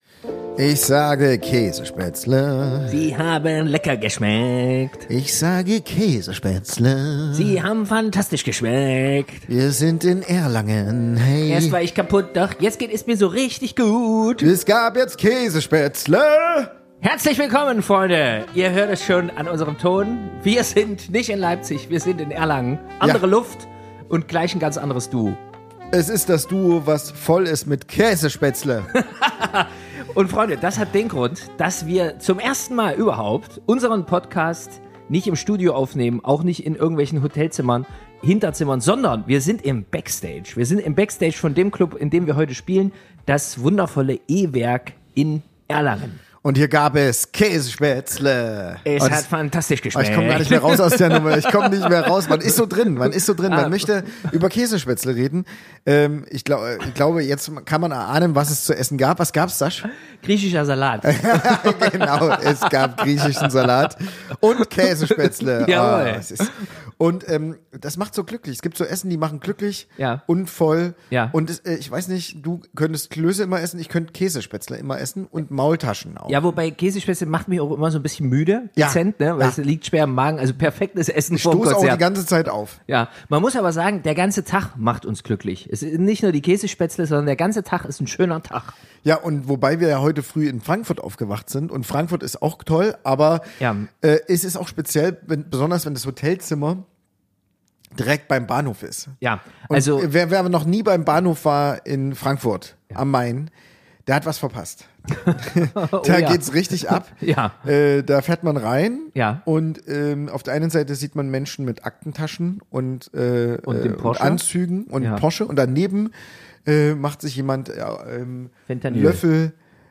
Beschreibung vor 5 Tagen Wir hören das Publikum schon beim Einlass rauschen, während unser Team noch in letzter Sekunde durch den Saal flitzt.
Melden uns zum ersten Mal direkt aus dem Backstage – wenige Minuten vor Konzertbeginn.